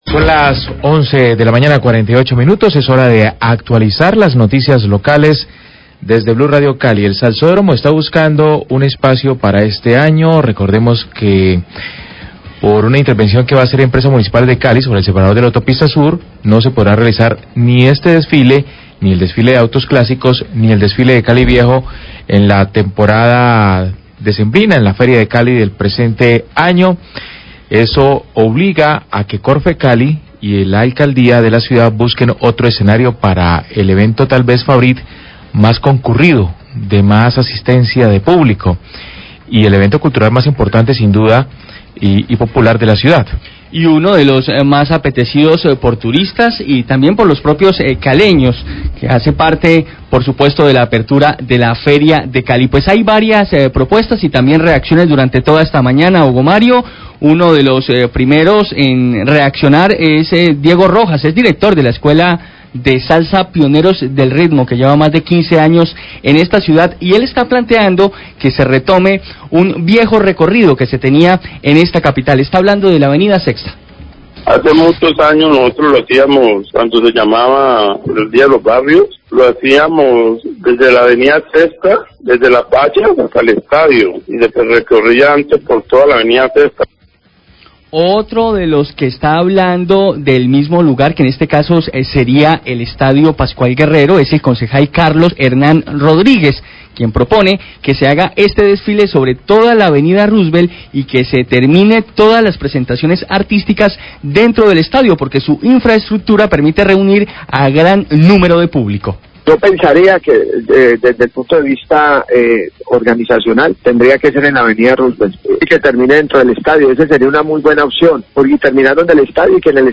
Radio
Debido a intervención de Emcali en la Autopista Suroriental, no se podrá realizar los desfiles de la Feria de Cali en este año. Se proponen otros lugares como la Av. 6ta, la Av. Rossevelt, el Estadio Pascual Guerrero, o el sector de Navarro. Al respecto, habla el alcalde Jorge Iván Ospina.